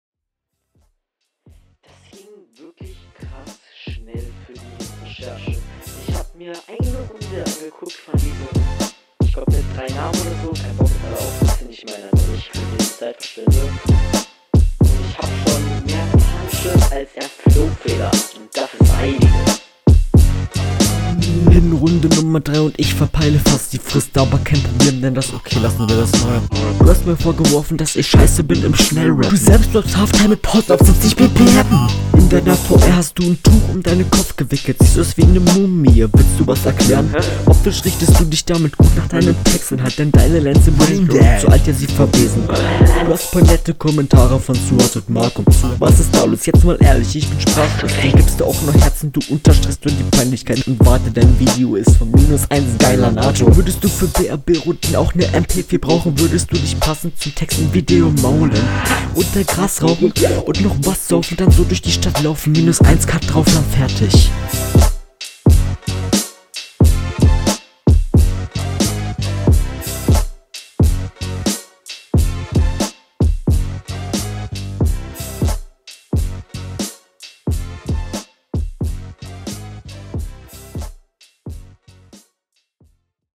Schöner flow.
Viel zu langes Intro. Hier wieder Geflüster.